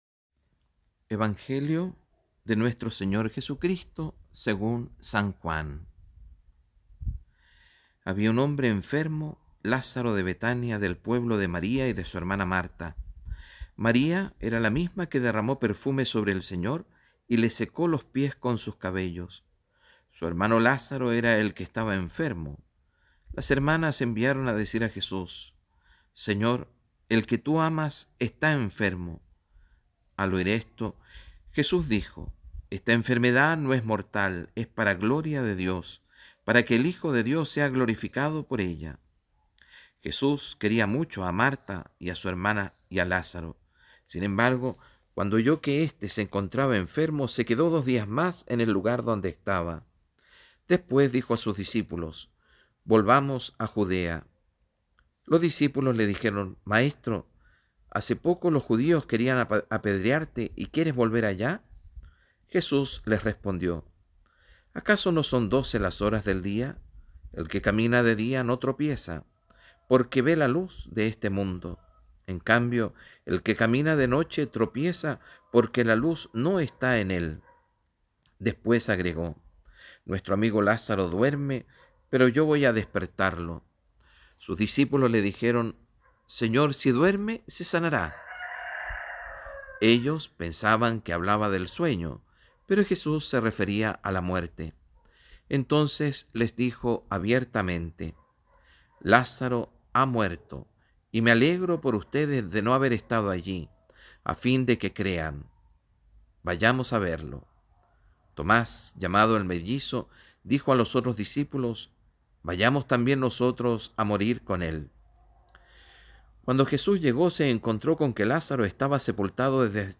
Homilía V domingo de Cuaresma y carta de nuestro párroco